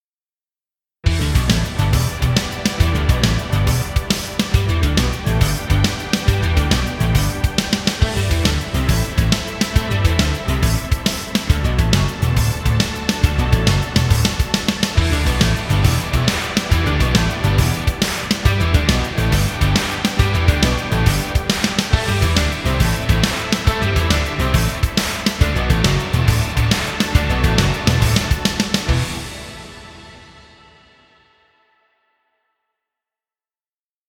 Stock Music.